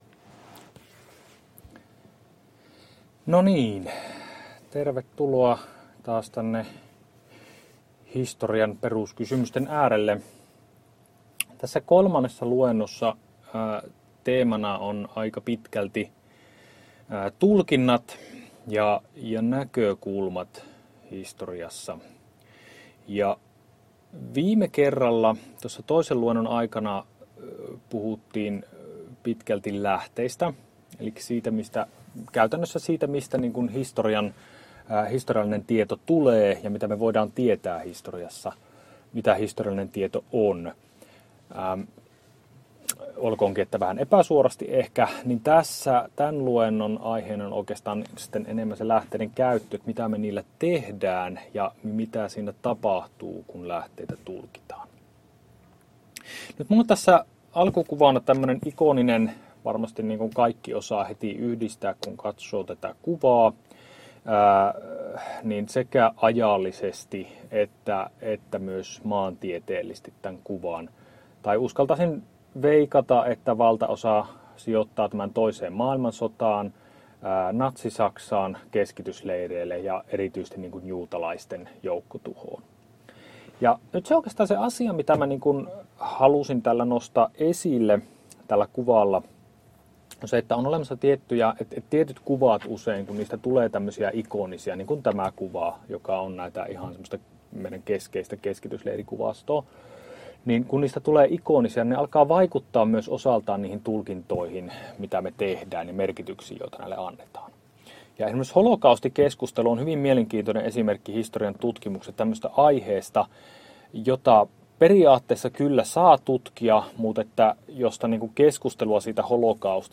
Luento 3 — Moniviestin